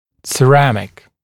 [sə’ræmɪk][сэ’рэмик]керамический